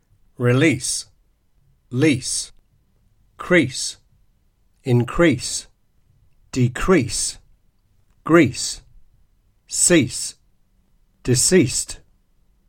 The /s/ words include release and lease; crease, increase and decrease (both verbs and nouns); also grease, cease and decease(d):
You should be able to hear that final /s/ is stronger and longer than final /z/, while the preceding vowel, conversely, is shorter before /s/ and longer before/z/.